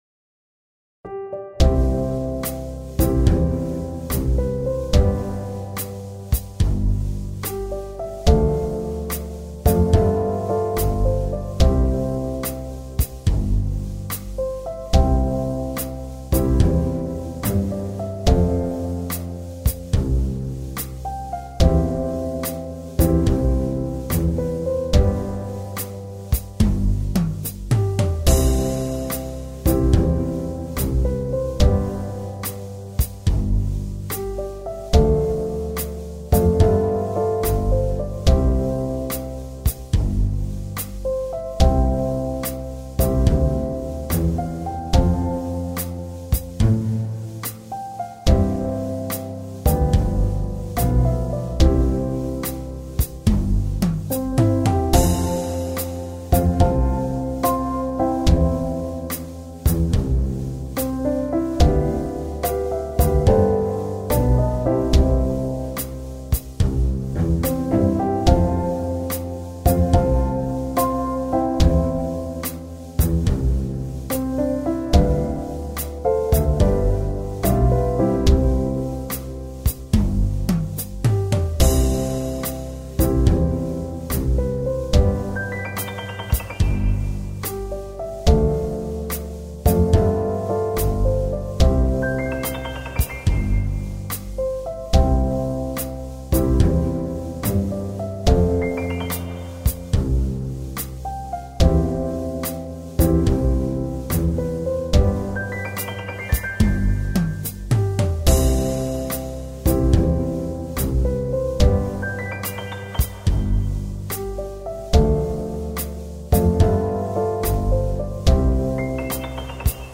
ジャズ明るい穏やか